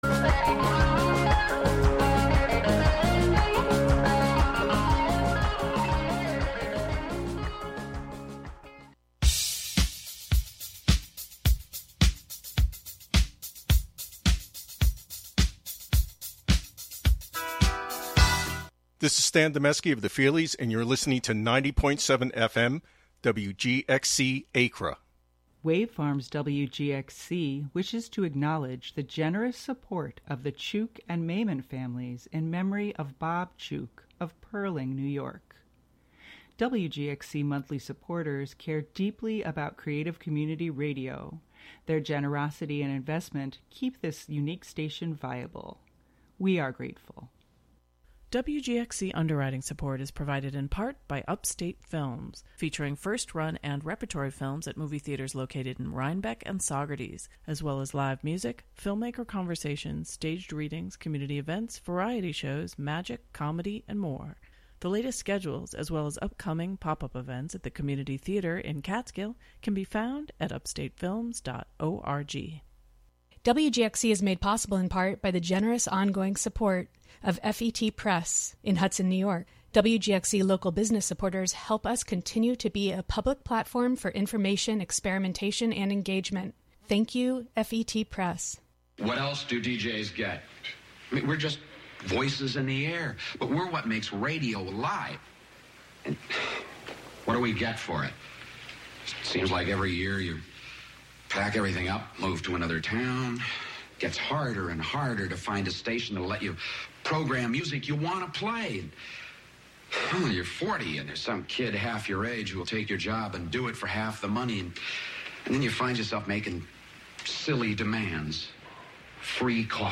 Baladas, Candombes, Llaneros, Tangos y mucho más.
Ballads, Candombe, Llaneros, Tangos, and much more.